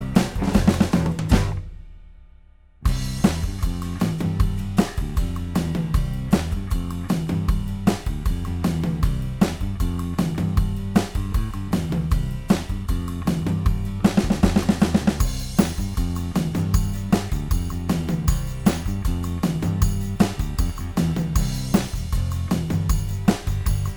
Minus All Guitars Rock 'n' Roll 2:25 Buy £1.50